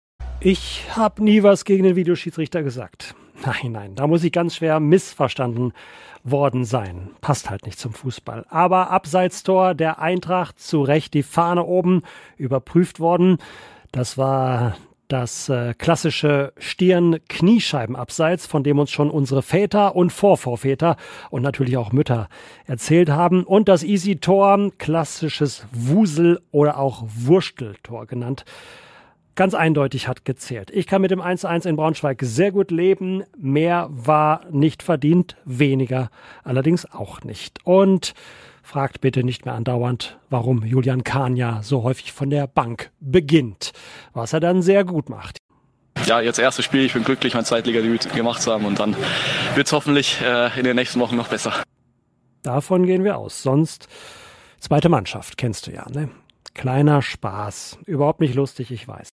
Kommentar nach dem Spiel